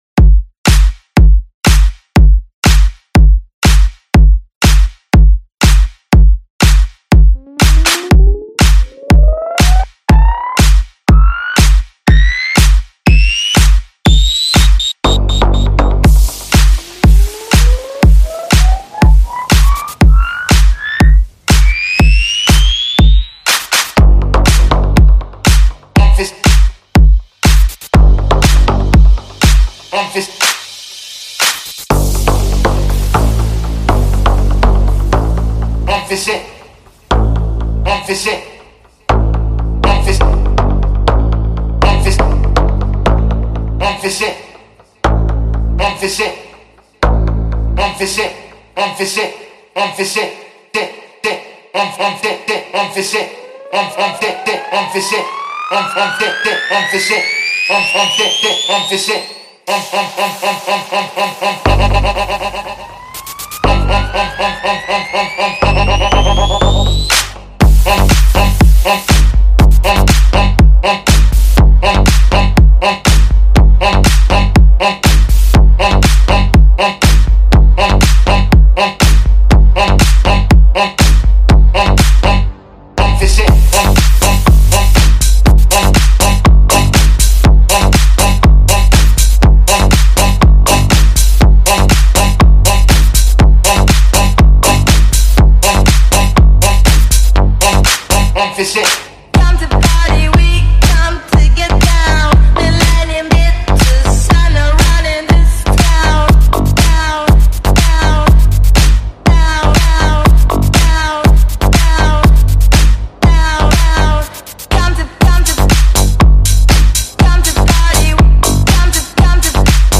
دانلود موزیک ساب دار وحشتناک سیستم ماشین